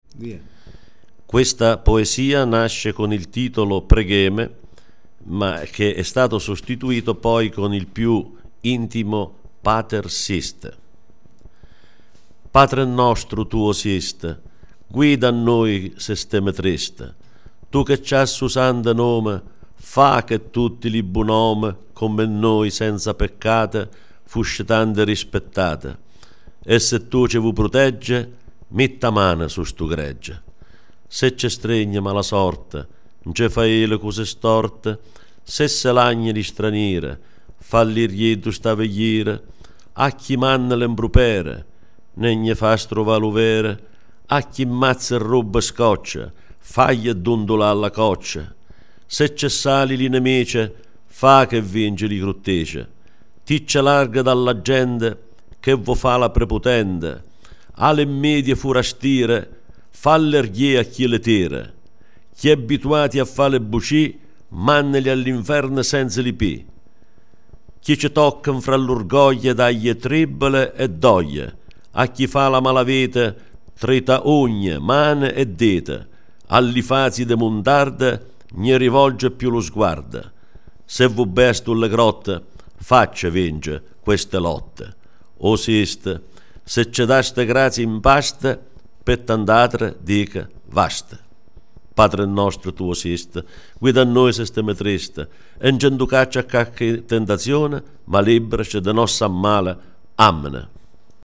in dialetto grottammarese